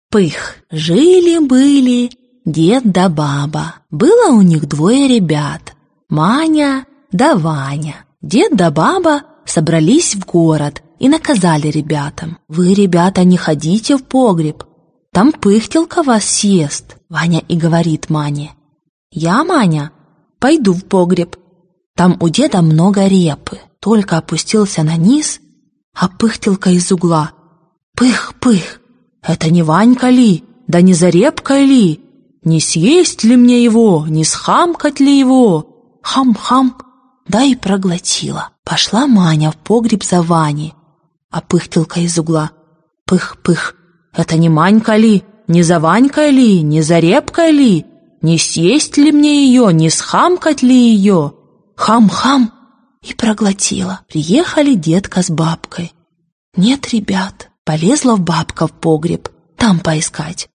Аудиокнига Русские народные сказки. Большая колекция | Библиотека аудиокниг